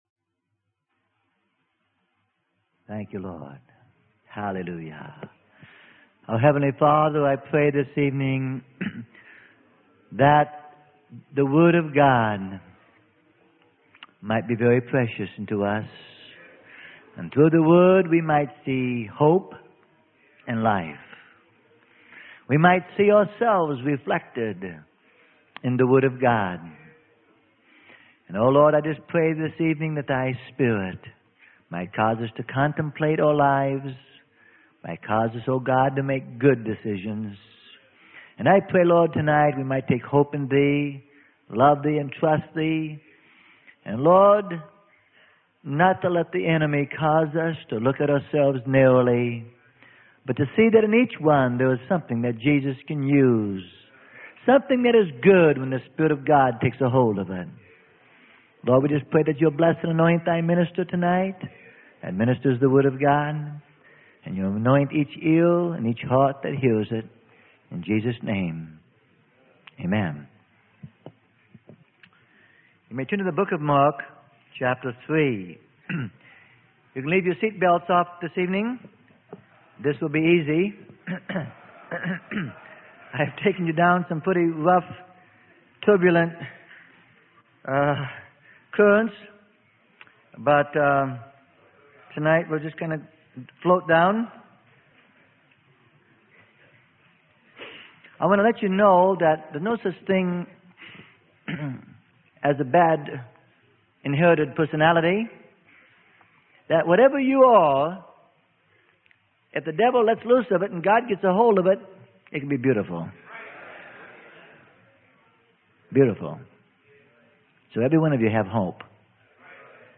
Sermon: Men Chosen to be Apostles - Freely Given Online Library